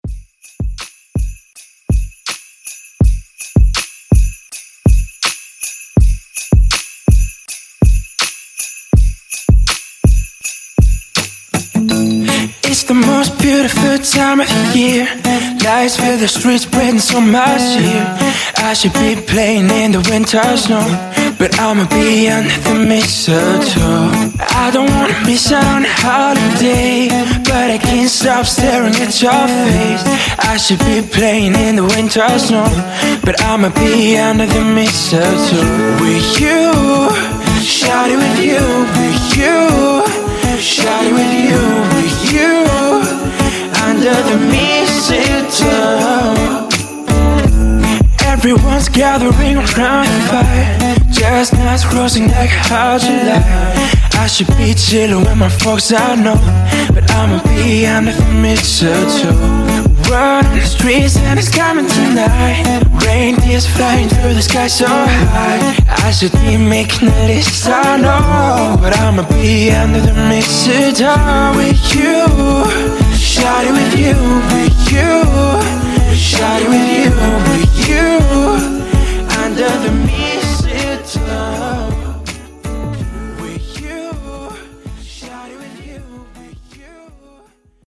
Genres: GERMAN MUSIC , HIPHOP , RE-DRUM
Dirty BPM: 89 Time